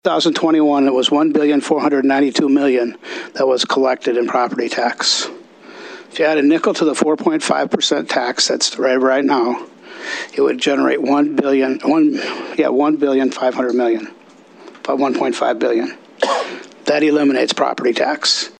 The “Study Committee on Property Tax Structure and Tax Burden” held their second meeting of the interim in Pierre.
Representative Tim Goodwin of Rapid City suggested to do away with property taxes altogether by replacing them with increased sales taxes.